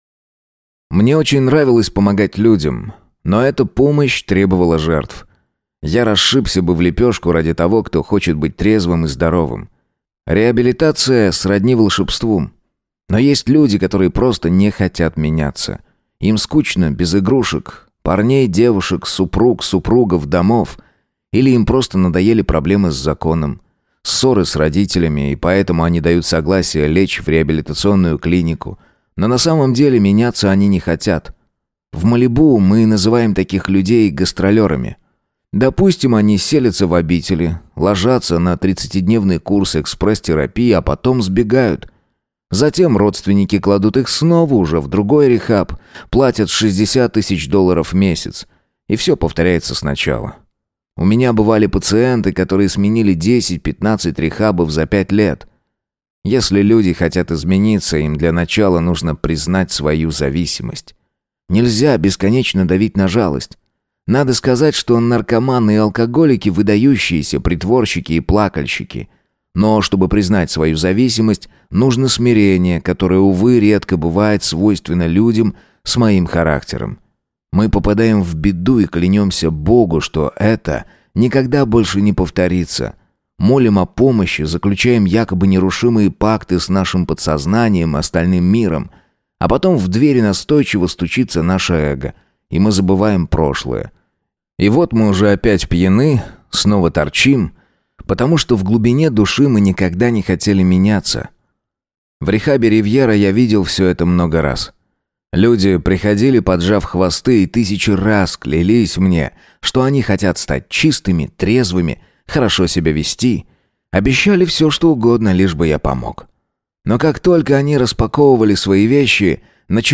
Аудиокнига Я забыл умереть | Библиотека аудиокниг